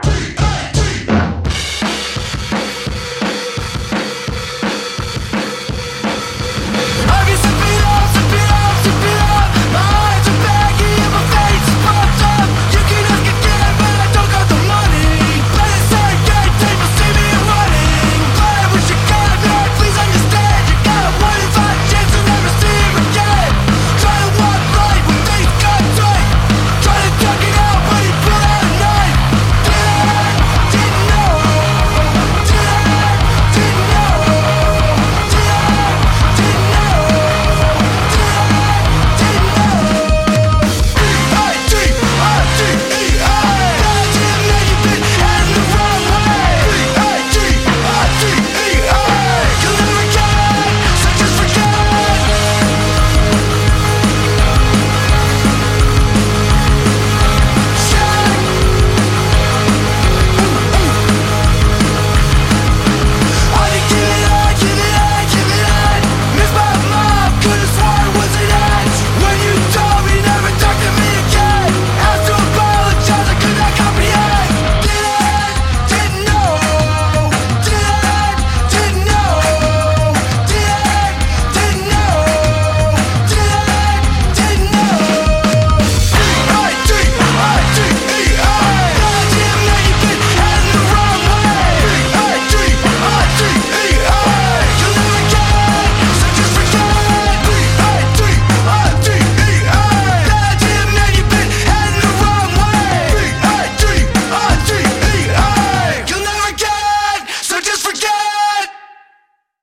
панк рок